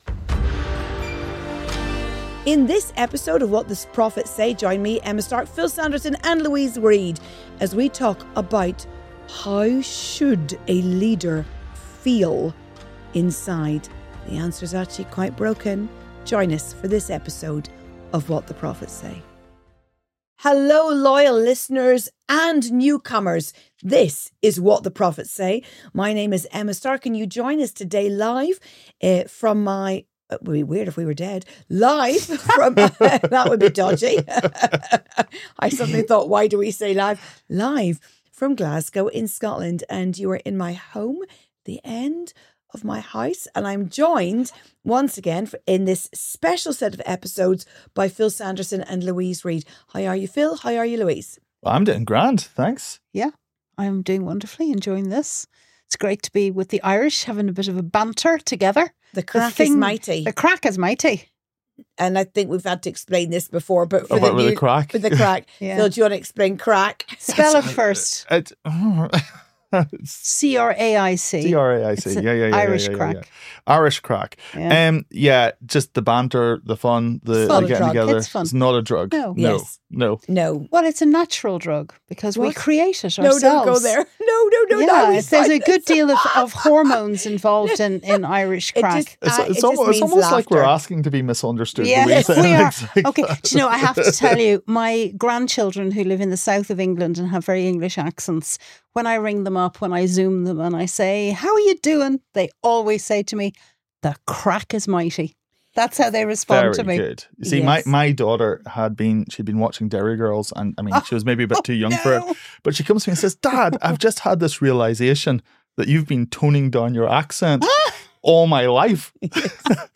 Prophets Get Personal: Surviving Betrayal, Shifting Seasons & Spiritual Burnout 44:13 Play Pause 2d ago 44:13 Play Pause Play later Play later Lists Like Liked 44:13 In this honest, tender, and raw episode, the prophets set aside their “thus says the Lord” for a vulnerable conversation about what they’re personally learning in a season of deep change, spiritual warfare, and soul-testing leadership.